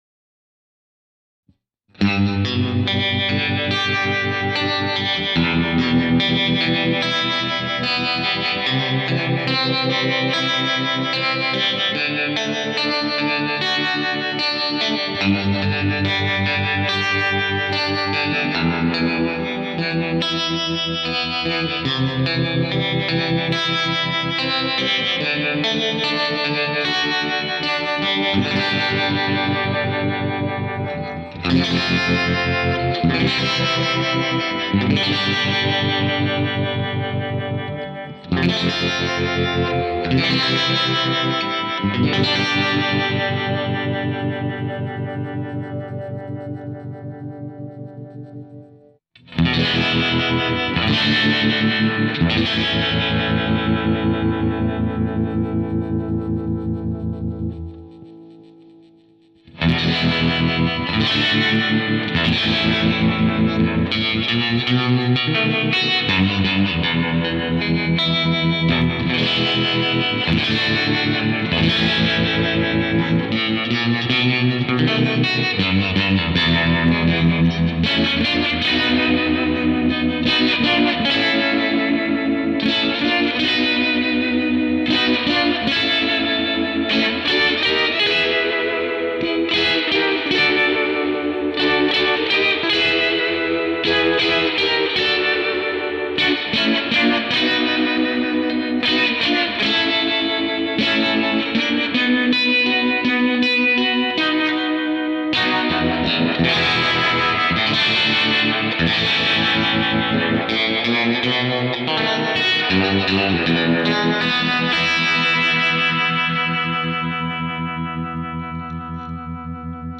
Harmonic tremolo - needs tweaking
Try to tune out the reverb if you can.